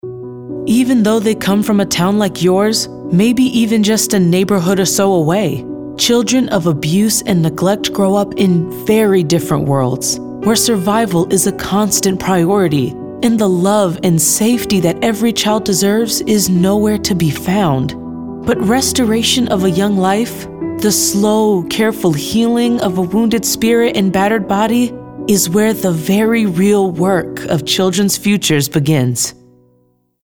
caring, concerned, real, serious, soft-spoken, thoughtful, warm